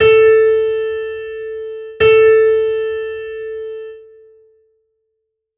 Audio nota LA
LA.wav